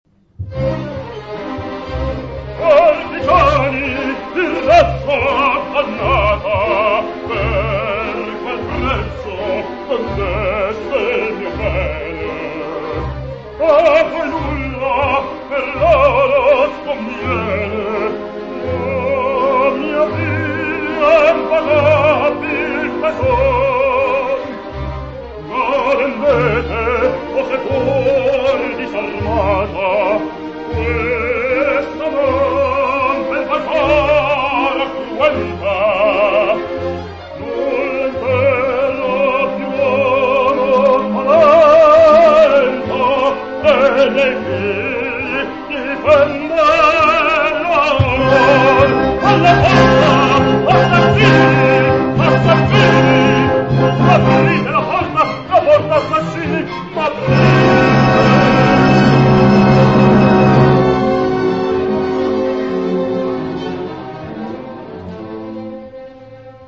Piccolo archivio multimediale delle passate stagioni artistiche del teatro Verdi di Buscoldo.